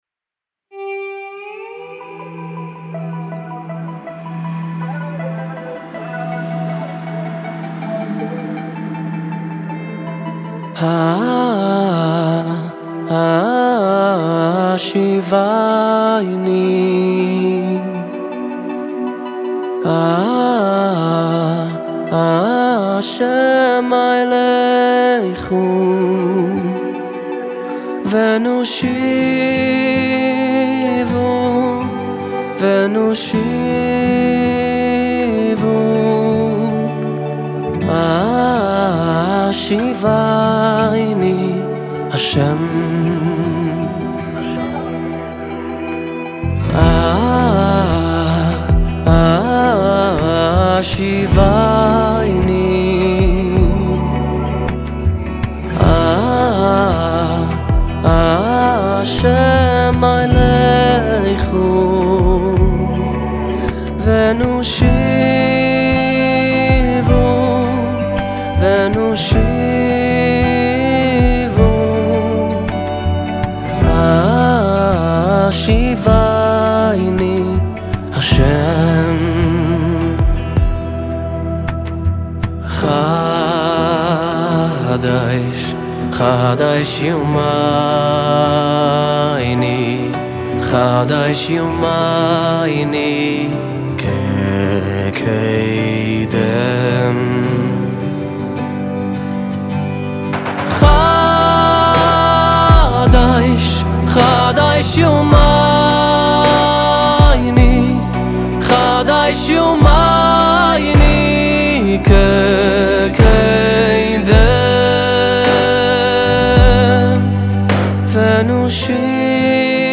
גרסה חסידית ומלאת רגש